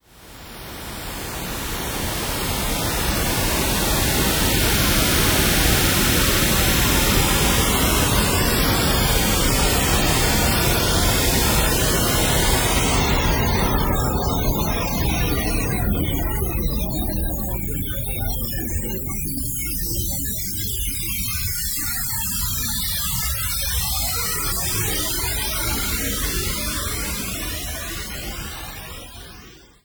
IO2OI stands for ‚input output to output input‘ and is an audiovisual combination work. Visual textures were generated from a serial mouse gesture documentation and these in turn were filtered and translated into sound art samples.